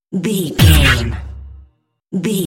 Dramatic hit deep electronic
Sound Effects
Atonal
heavy
intense
dark
aggressive
the trailer effect